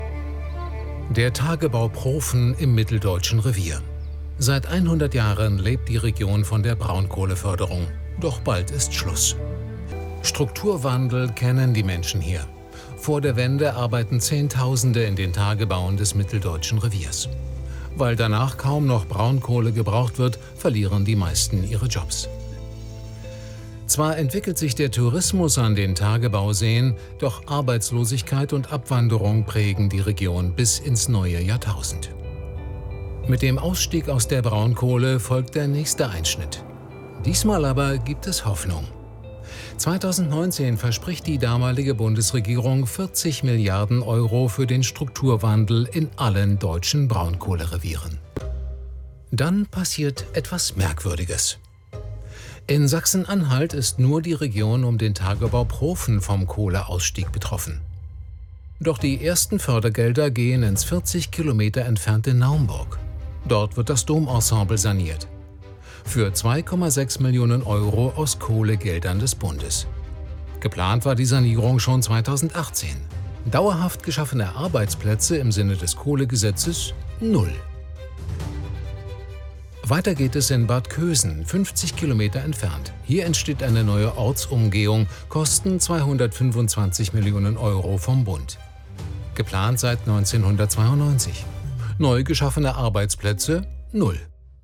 sehr variabel
Mittel plus (35-65)